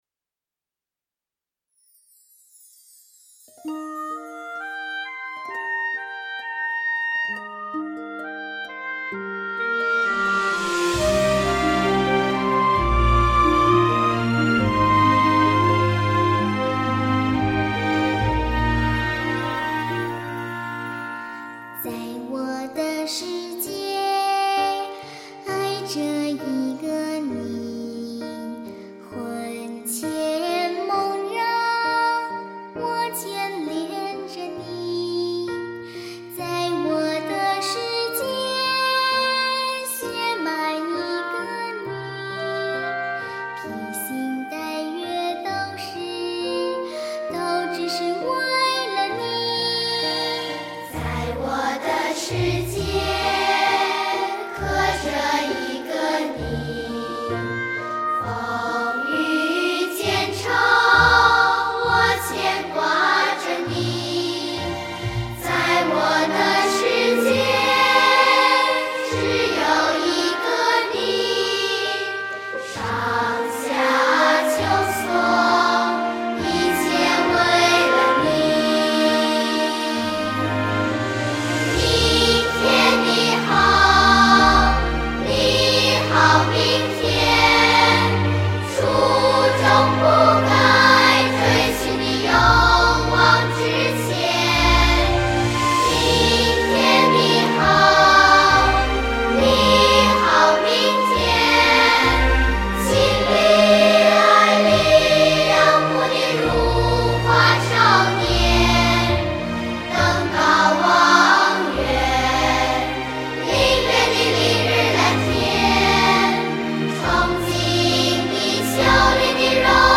歌曲旋律优美，歌声婉转、娓娓道来